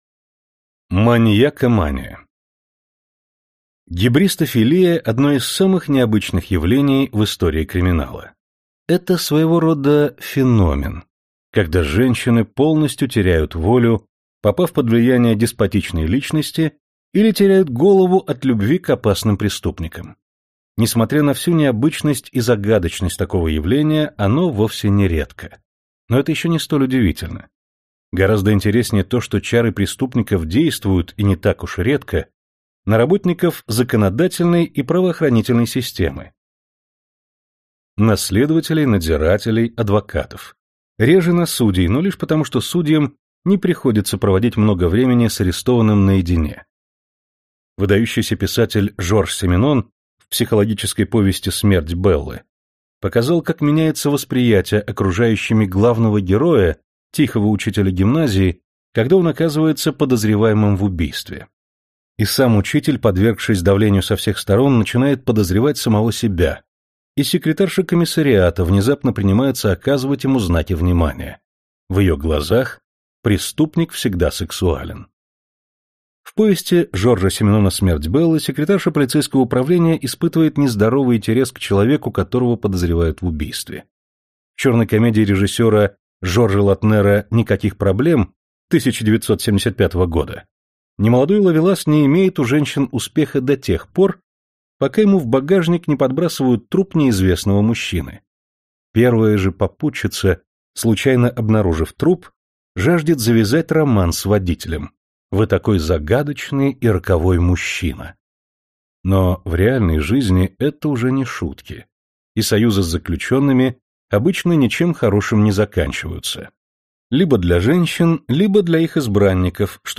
Аудиокнига Криминалистика. Борьба со спрутом | Библиотека аудиокниг